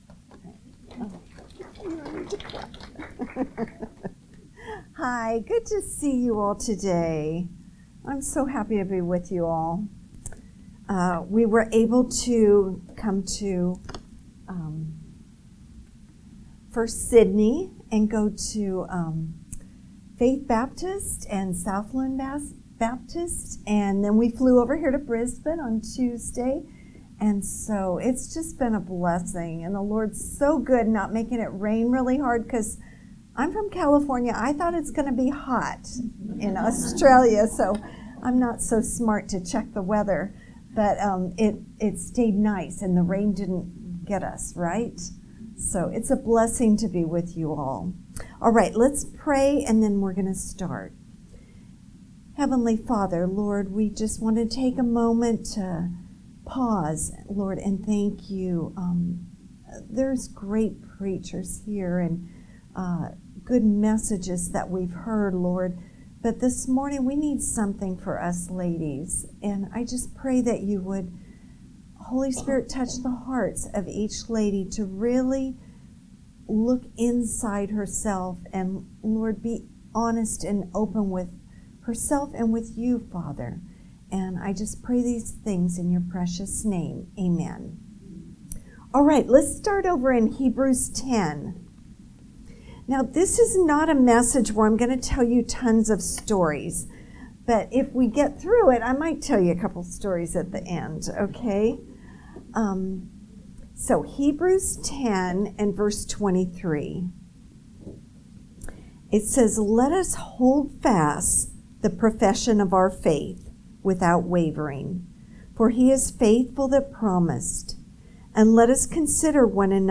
Guest Speaker
Current Sermon
Tue AM - Ladies' Session Leadership Conference 2024